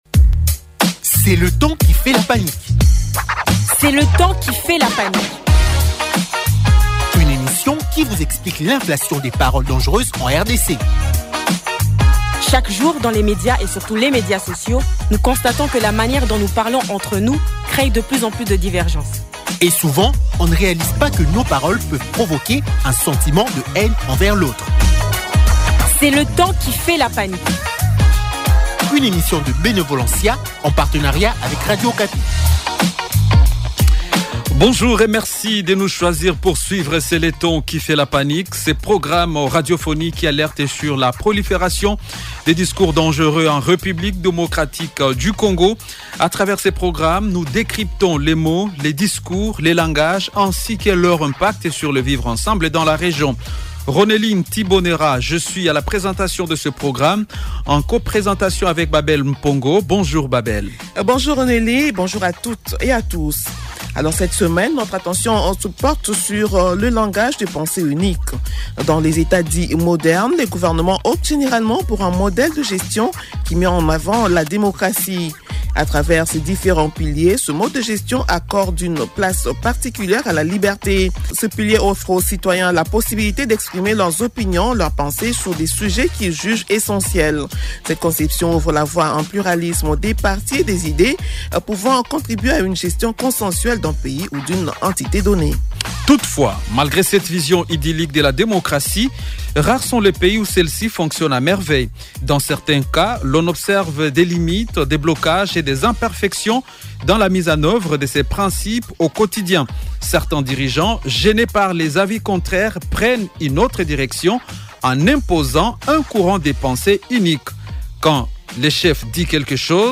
Pour en discuter, nous avons le plaisir de recevoir deux invités.